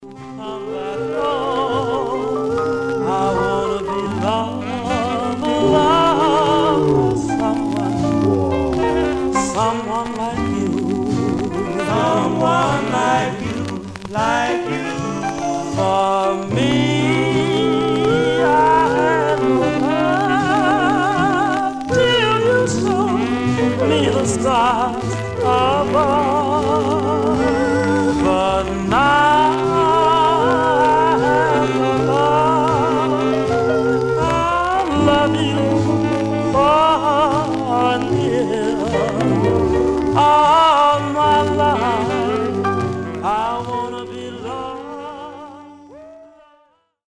Stereo/mono Stereo
Male Black Group